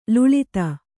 ♪ luḷita